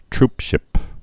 (trpshĭp)